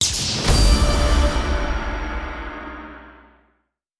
scatter_win.wav